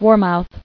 [war·mouth]